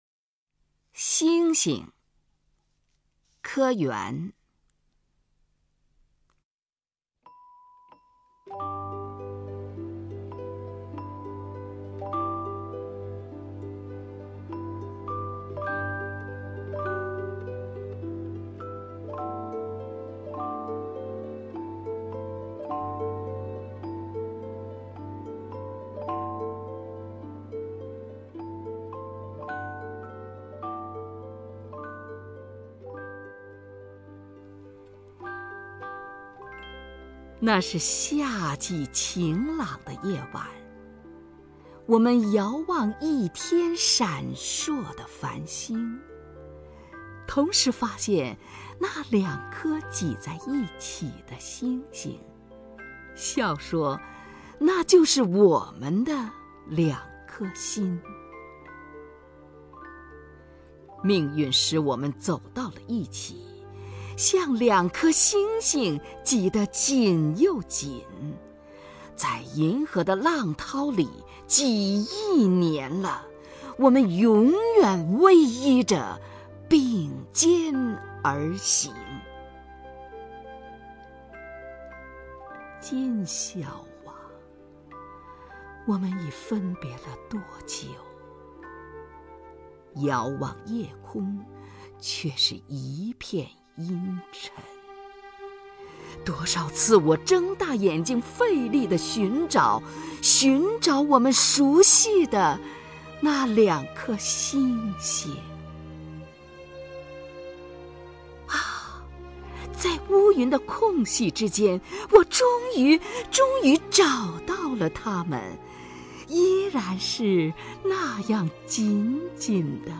首页 视听 名家朗诵欣赏 虹云
虹云朗诵：《星星》(柯原)　/ 柯原